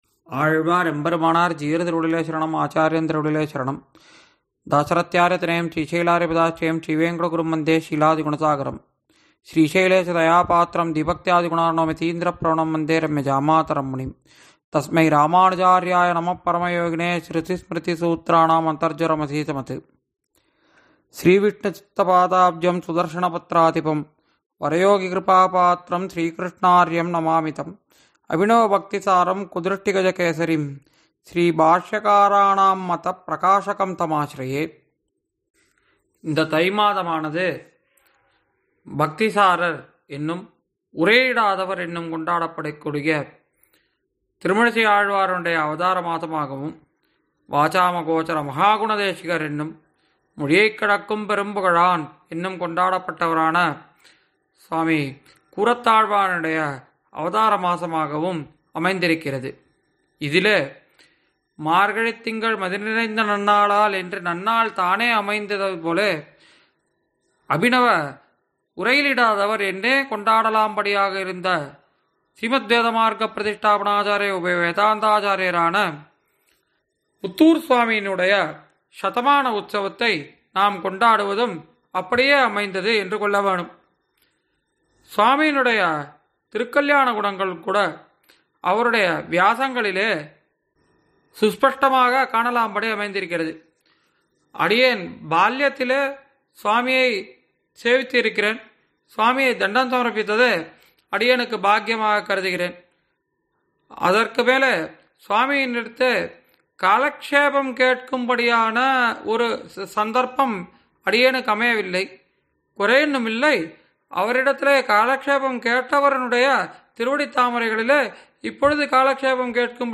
ஆறெனக்கு நின் பாதமே சரண் அளிக்கும் உபந்யாசத் தொடரில் –